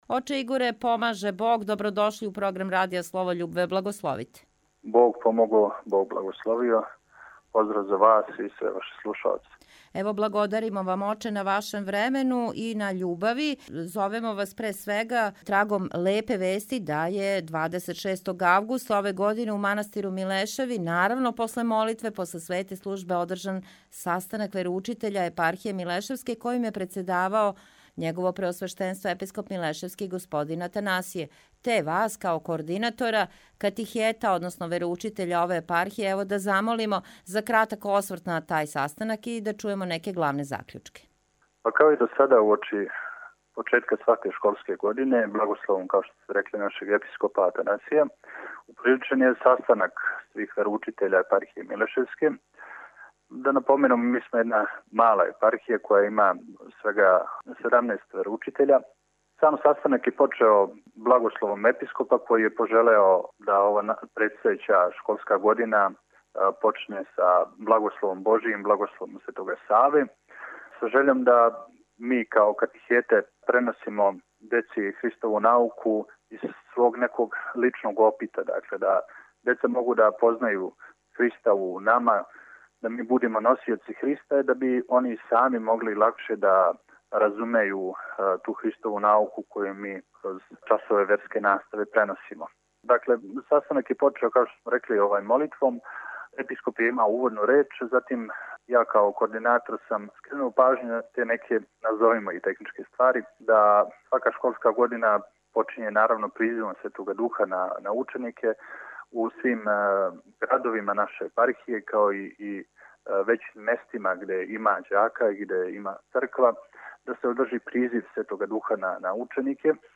У навечерје претпразништва Успења Пресвете Богородице, 26. августа 2020. године, у манастиру Милешеви је након свете службе одржан састанак вероучитеља Епархије милешевске којим је председавао Његово Преосвештенство Епископ милешевски г. Атанасије. Звучни запис разговора